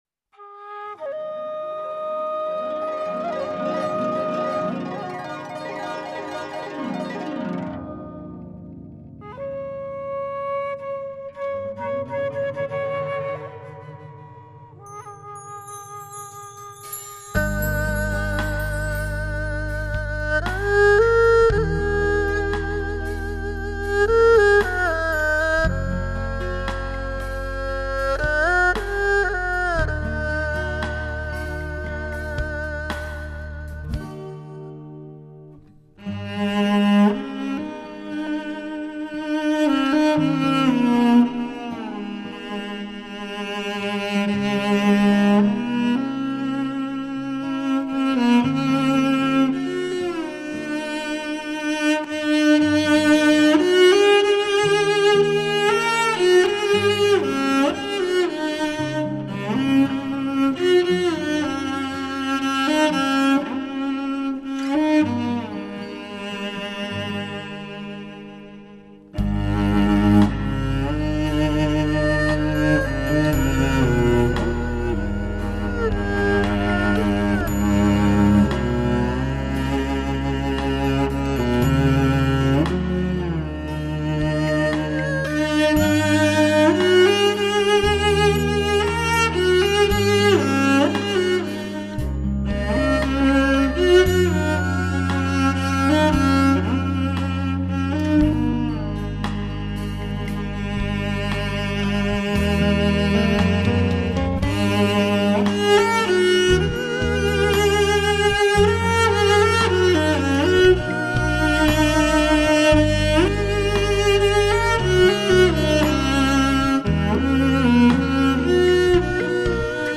类 型：流行